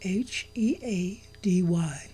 I am recording my voice simply speaking.
I am using a CAD Audio U1 Handheld USB Recording Microphone with a sleeve on it to avoid hissing.
The attached files are not too loud, so what is the relevance of turning the volume down? The “h” is intrusive, otherwise they are reasonable in my opinion.